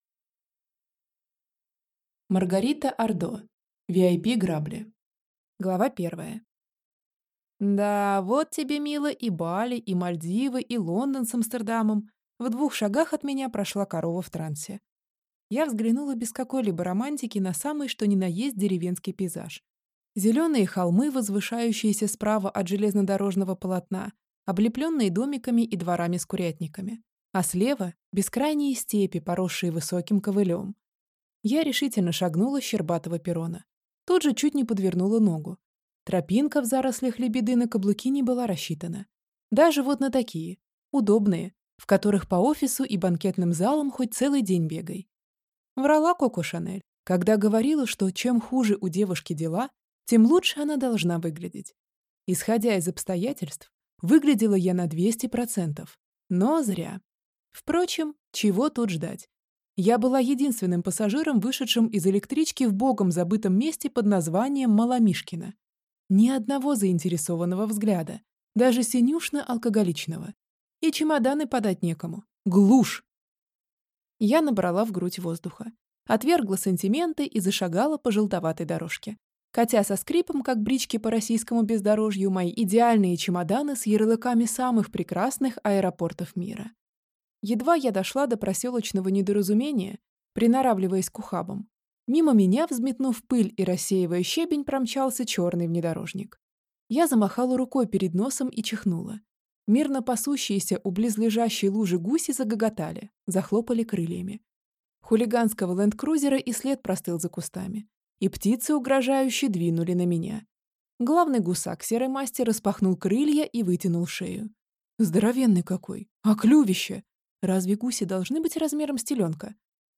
Аудиокнига VIP Грабли | Библиотека аудиокниг